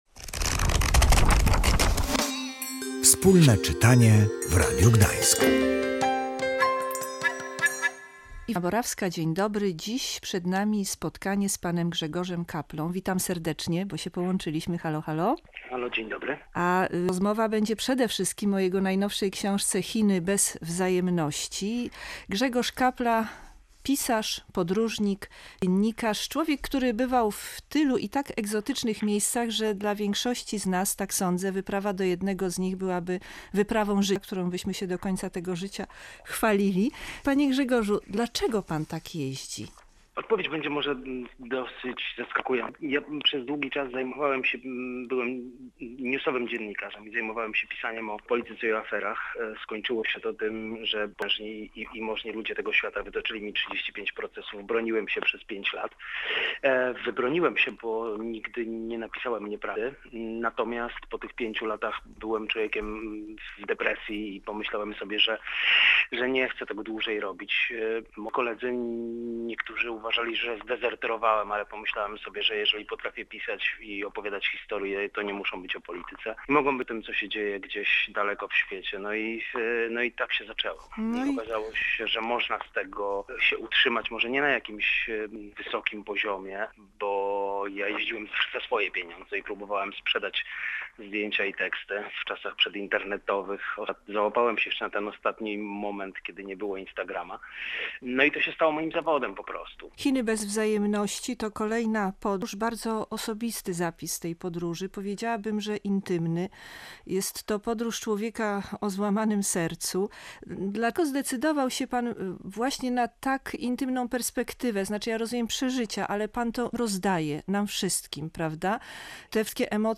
„Chiny bez wzajemności”, rozmowa